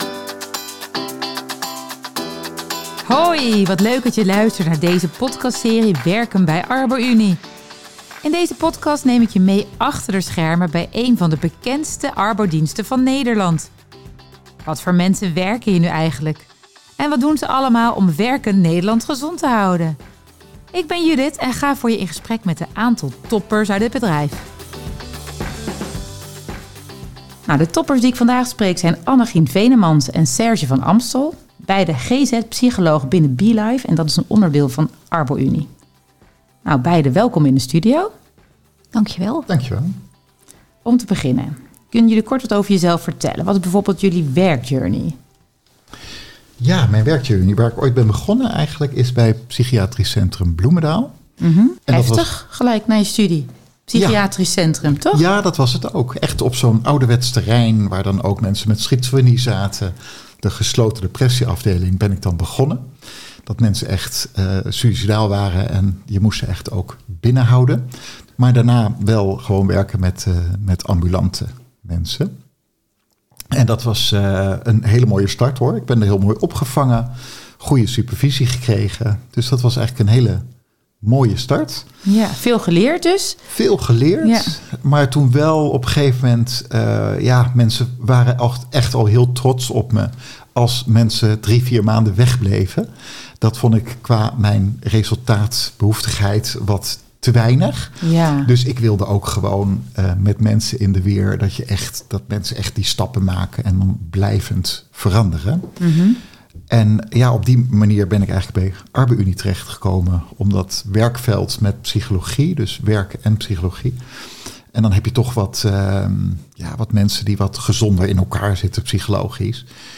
In elke aflevering gaan we met een collega in gesprek die alles kan vertellen over de werkzaamheden, mensen en ervaringen van werken bij Arbo Unie.